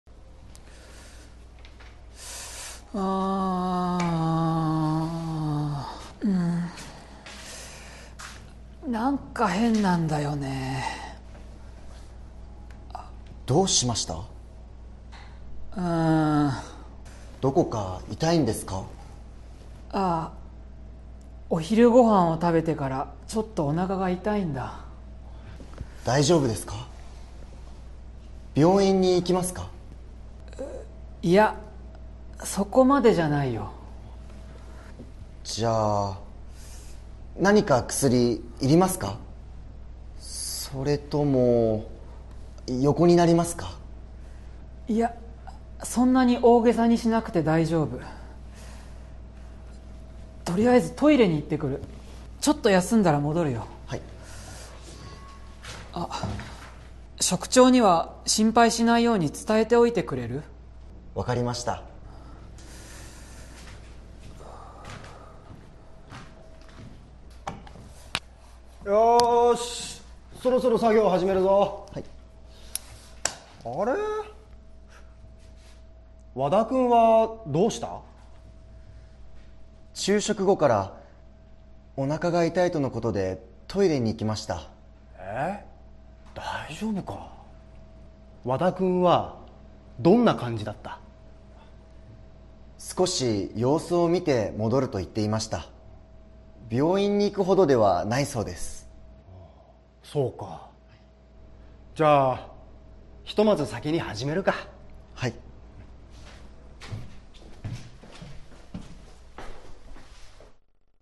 Role-play Setup
Conversation Transcript